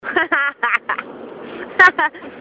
Laugh2